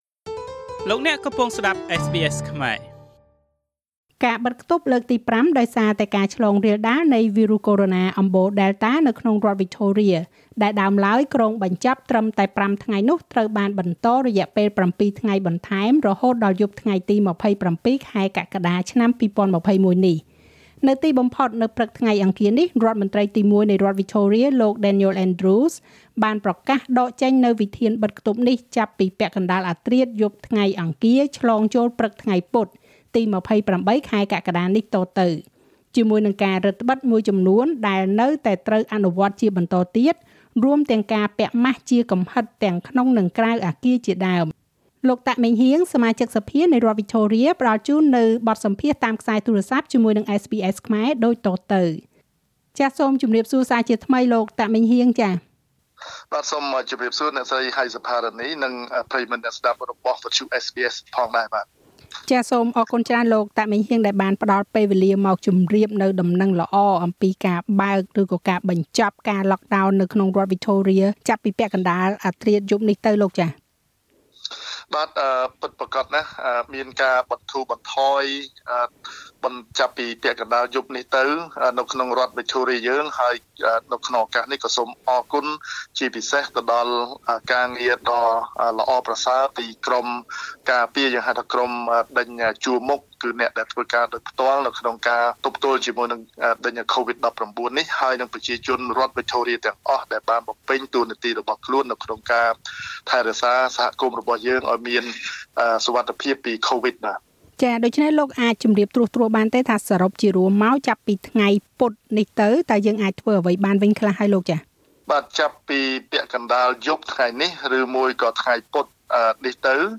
លោកតាក ម៉េងហ៊ាង សមាជិកសភានៃរដ្ឋវិចថូរៀពន្យល់បន្ថែម។